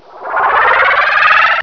Arch-Vile
Sight